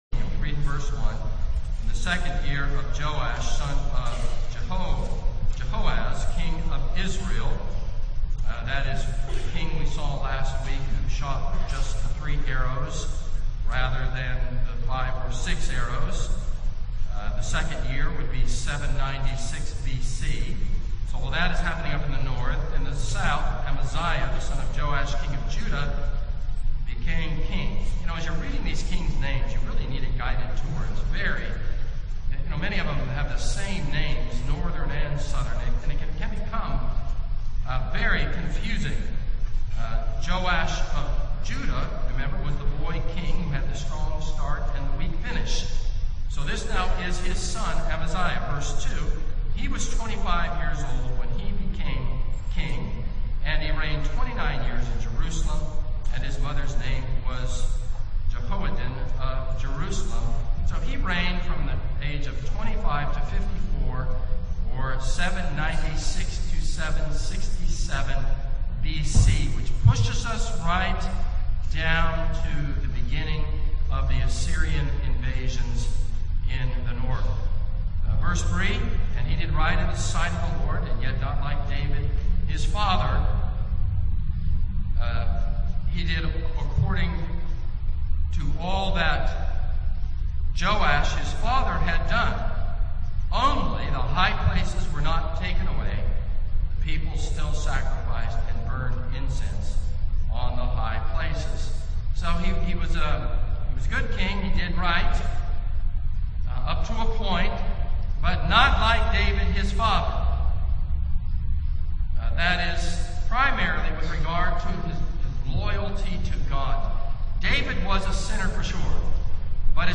This is a sermon on 2 Kings 14.